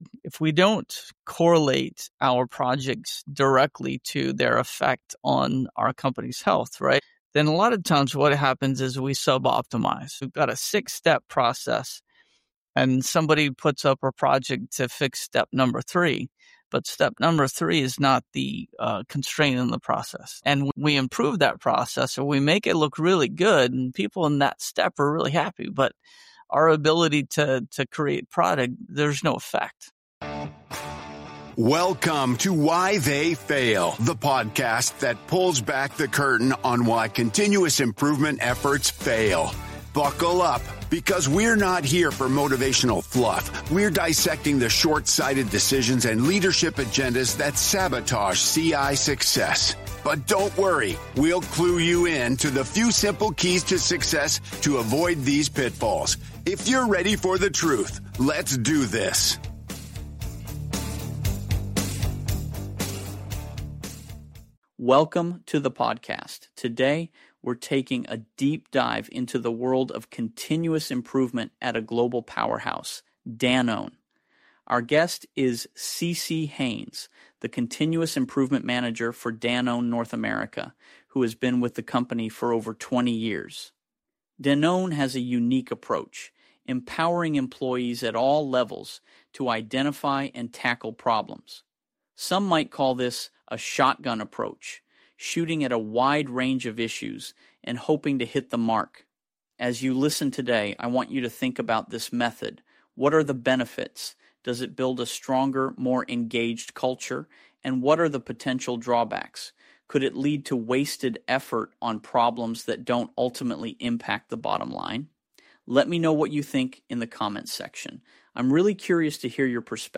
This conversation centers on a key debate.